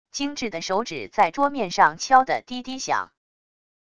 精致的手指在桌面上敲得滴滴响wav音频